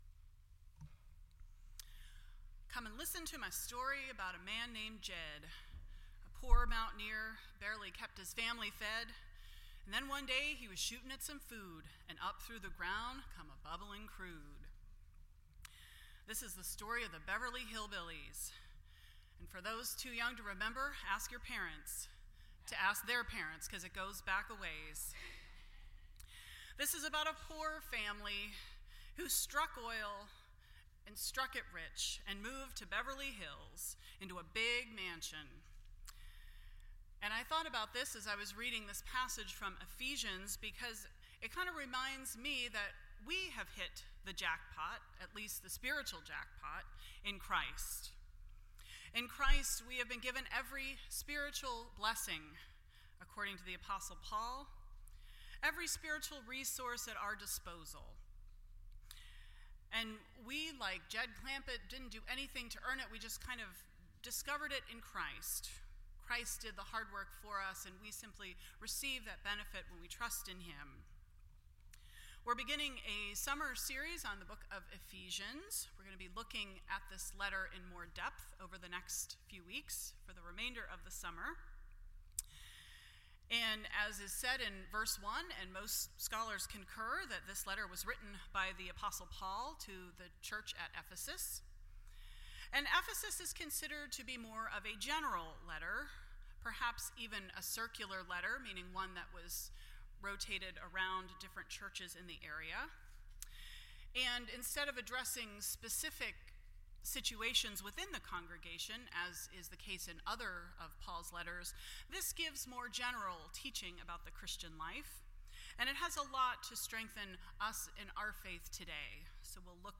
Ephesians Service Type: Sunday Morning %todo_render% Share This Story